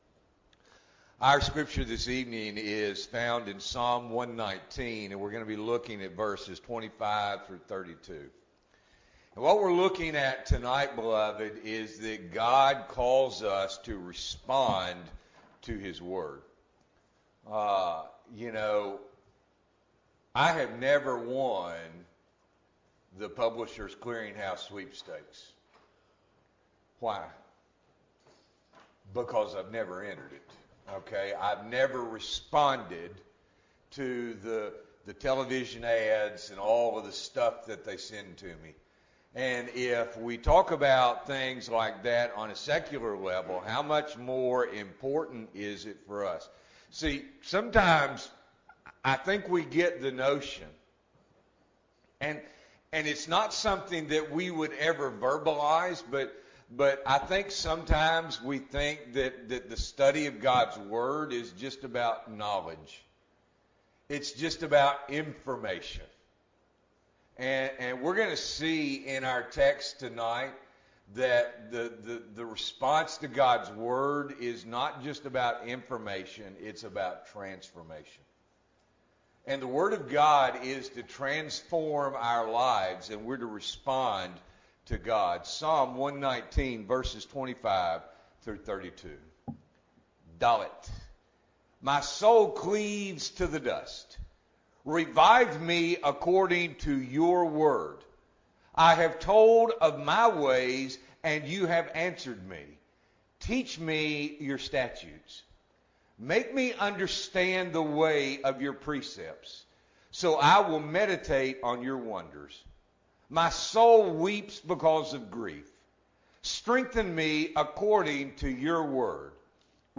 March 6, 2022 – Evening Worship